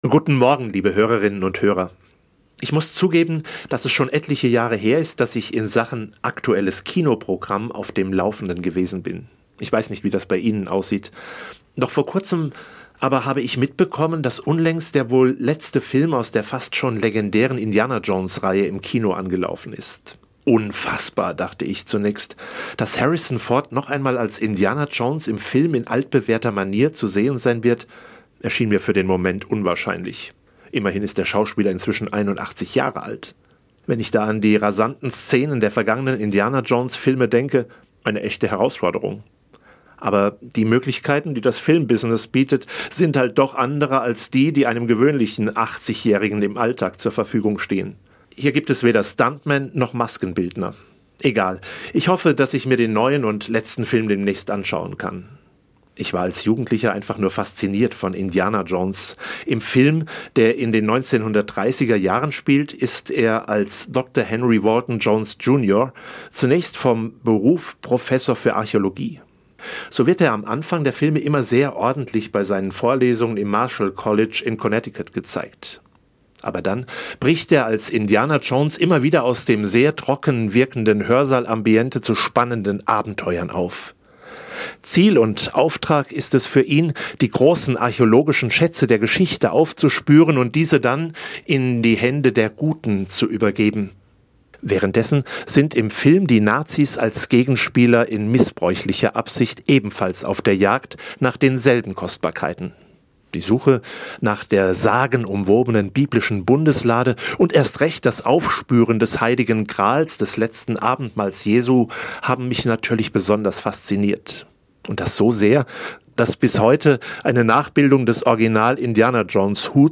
Guten Morgen, liebe Hörerinnen und Hörer!